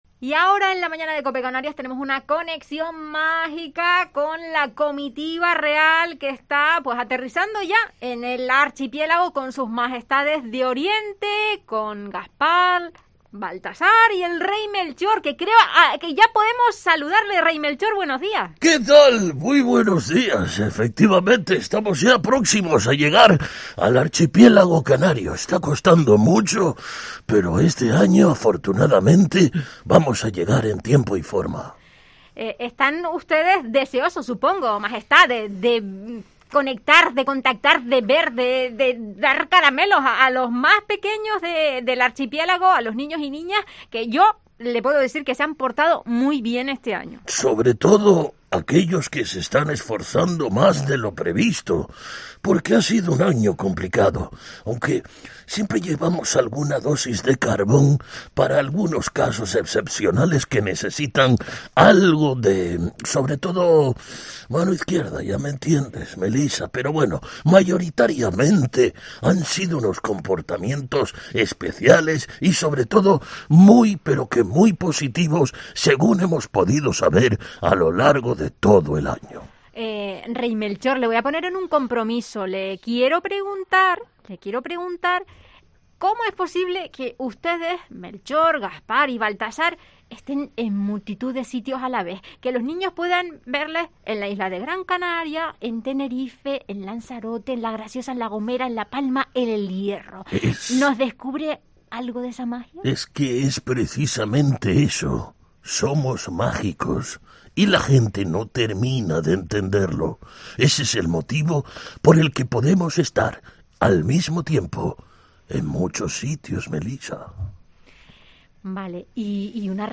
Entrevista al Rey Mago Melchor en La Mañana en Canarias